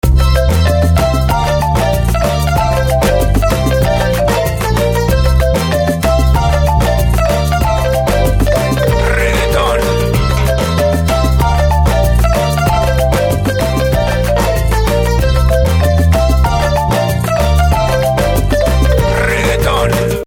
After listening to the ones in that collection, I chose one of the bongo loops that seemed to go well with my drum loop.
And I did the same for an Sdrumloop loop, Shaker loop, snare loop, and finally a Timbale loop.
From the Malstrom patch folder I chose a subby bass which I doubled with a bass sound from the Reason Factory sound bank, and I also chose an accordion sound the latter of which I added reverb to, using a preset from the RV7000.
I really wanted a kind of lean melody line above everything else going on so I chose a xylophone sound from the Orkester Sound Bank to round out my music.
At any rate, I created a fun, rhythmic little piece of music using Reggaeton, which you can listen to by clicking the link below: